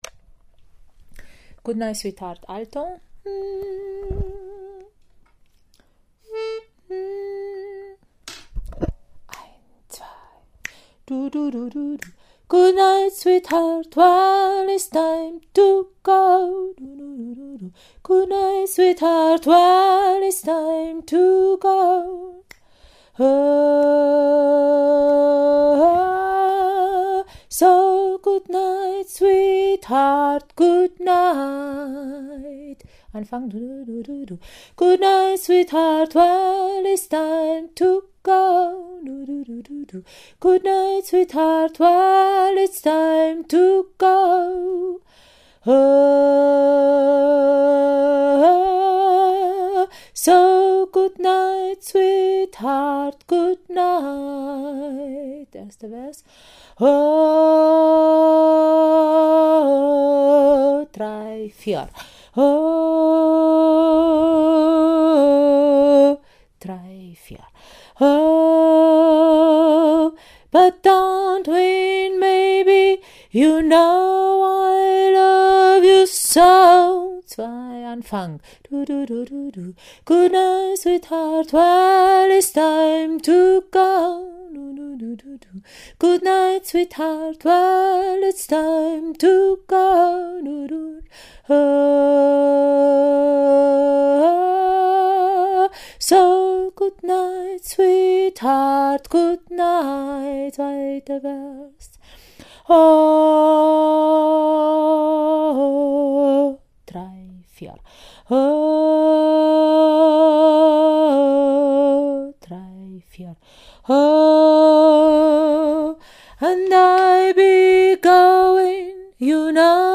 Goodnight-Sweetheart-Alto.mp3